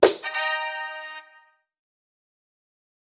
Media:BarbarianKing_evo2_dep.wav 部署音效 dep 在角色详情页面点击初级、经典、高手和顶尖形态选项卡触发的音效